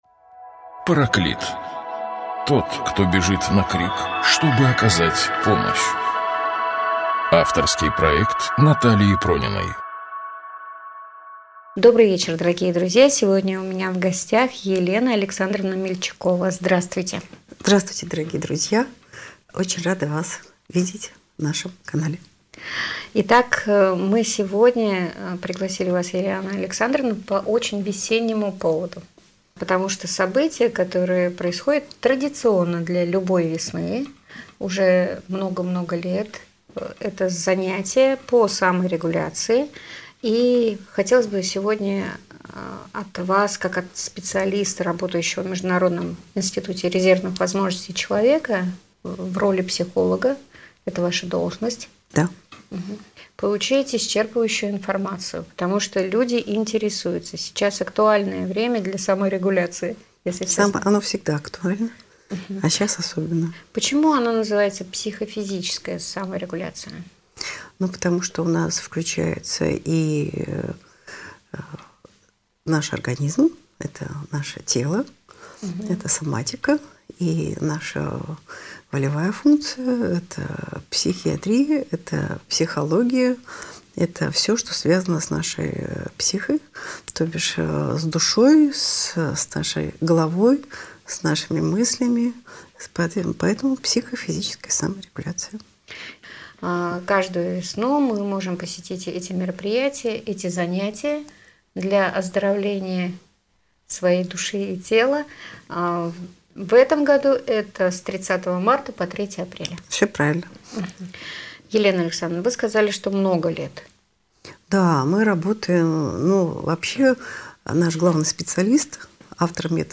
Гость эфира